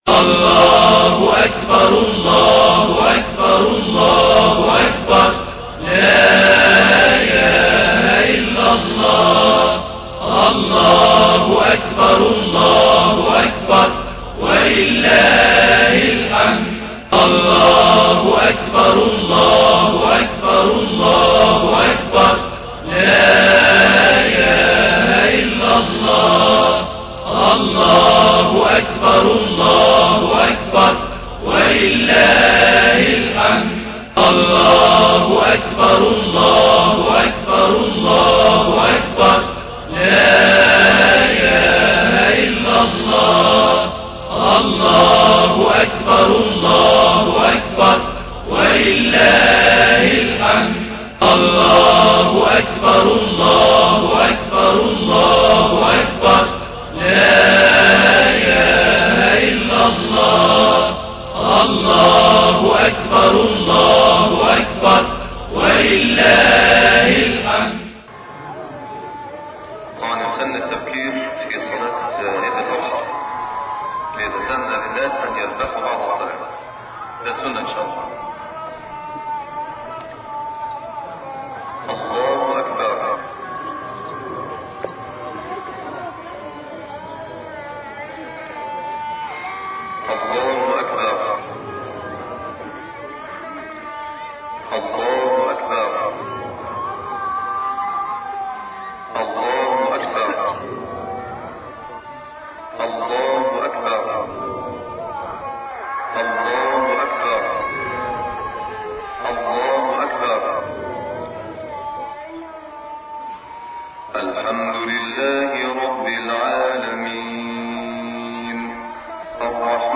صلاة العيد